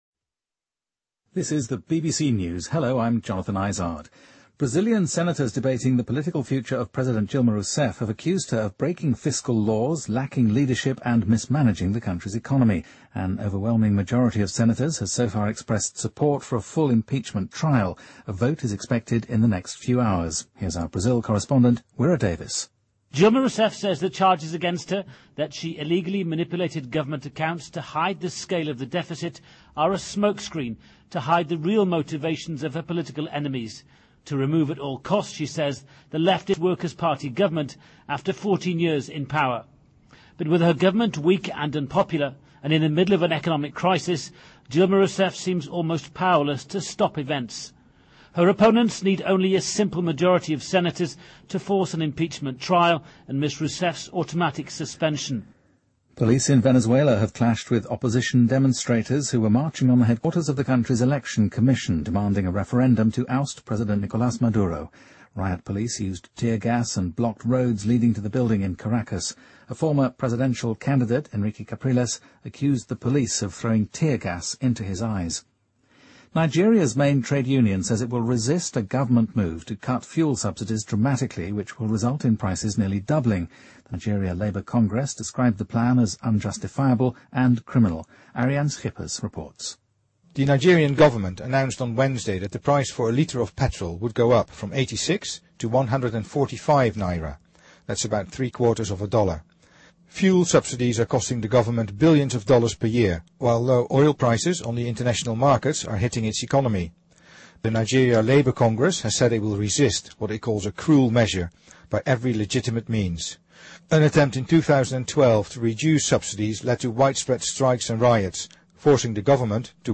BBC news,谷歌全面禁止高利贷产品广告
日期:2016-05-15来源:BBC新闻听力 编辑:给力英语BBC频道